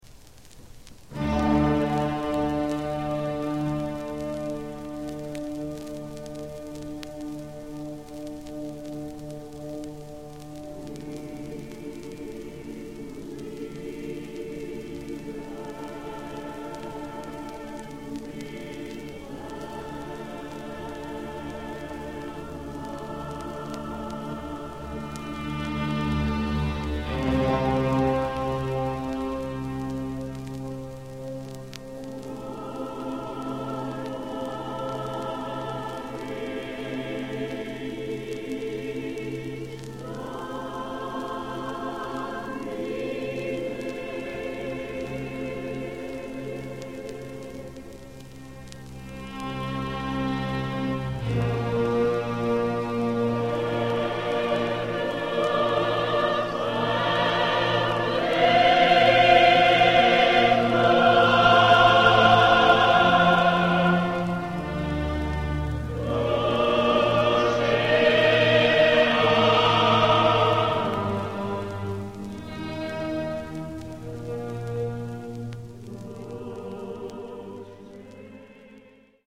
(1938年5月30-31、6月1日リヨン、サン＝ジャン大聖堂録音)